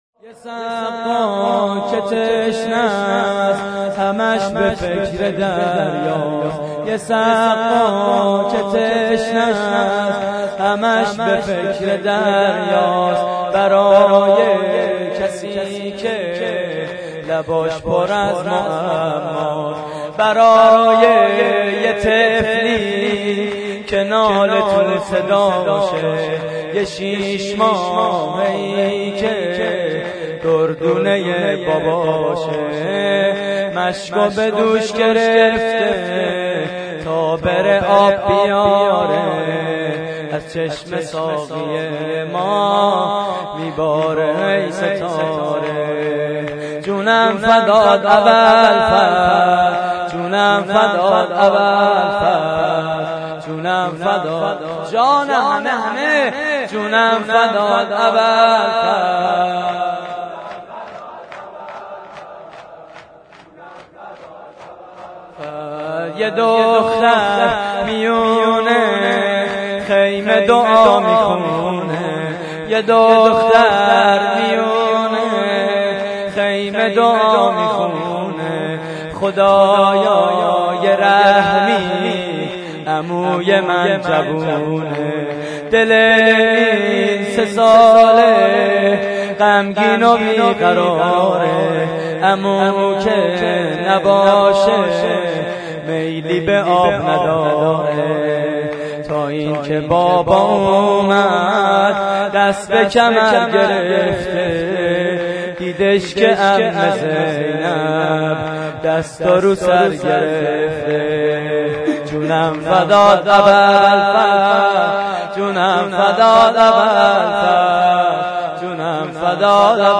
شور: یه سقا که تشنه‌ست
مراسم عزاداری شب تاسوعای حسینی